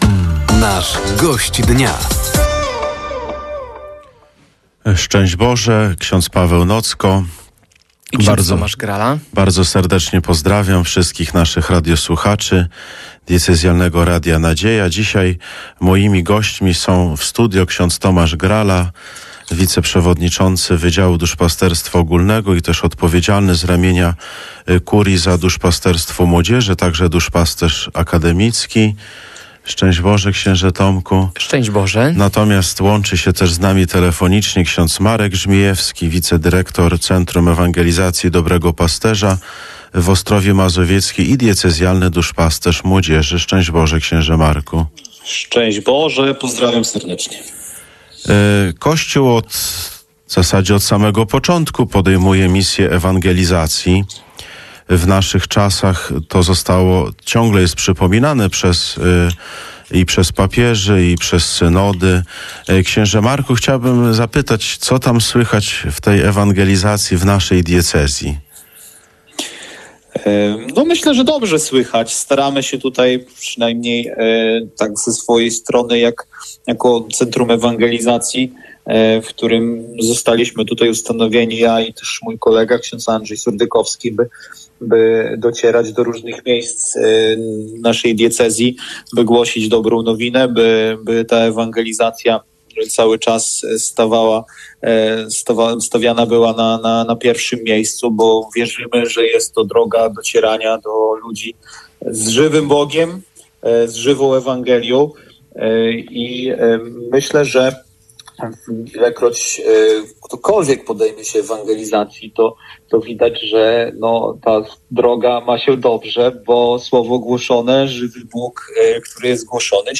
Pozostałe audycje z cyklu Gość Dnia Radia Nadzieja: